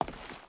Footsteps / Stone
Stone Chain Walk 4.wav